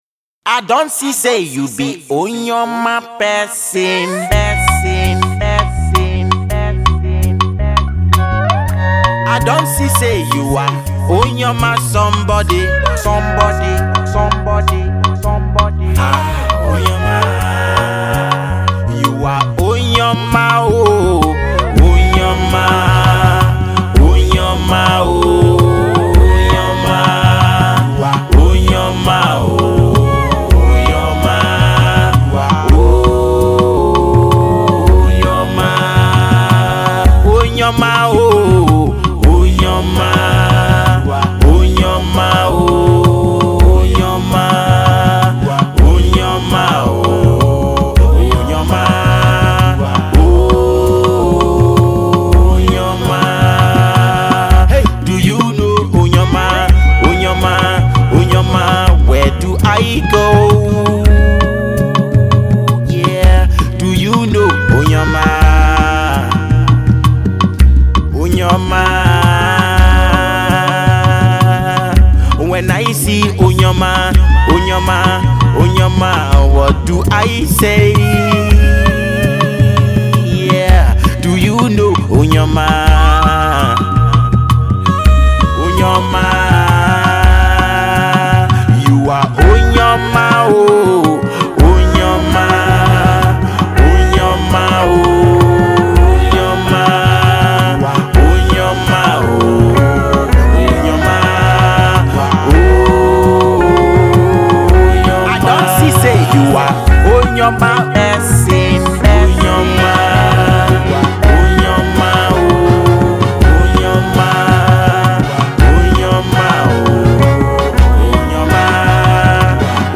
Alternative Pop
religious undertone